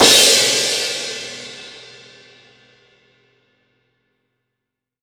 Percs
PERC.108.NEPT.wav